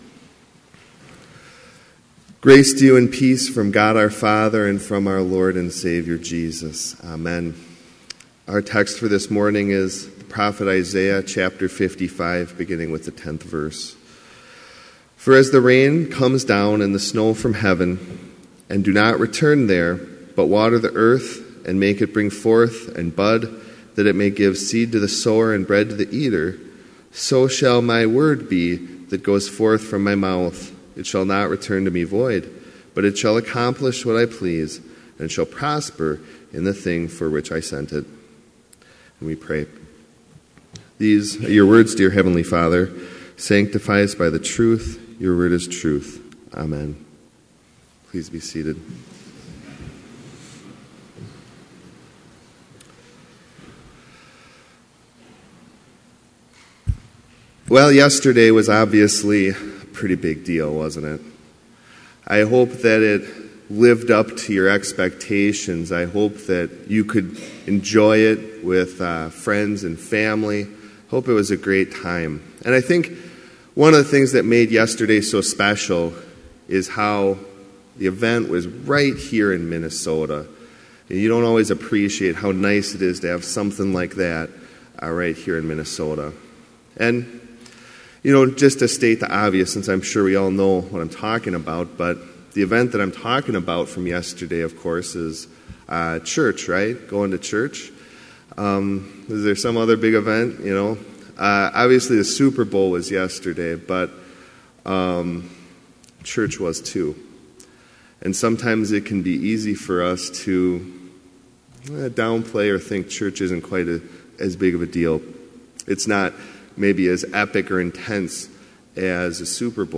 Complete service audio for Chapel - February 5, 2018